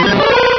Cri d'Embrylex dans Pokémon Rubis et Saphir.